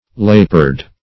Lepered \Lep"ered\ (-[~e]rd), a. Affected or tainted with leprosy.